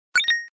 SBeepKey.ogg